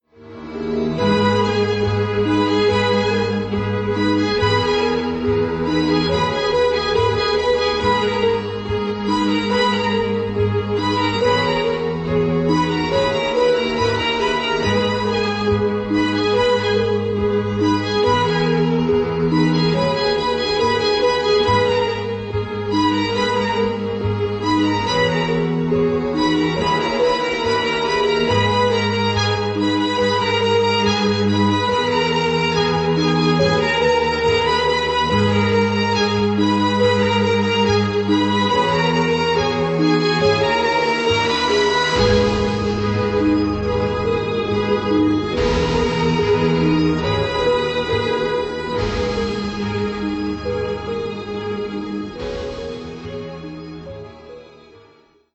• Качество: 256, Stereo
красивые
грустные
спокойные
без слов
скрипка
печальные
Очень трогательная инструментальная мелодия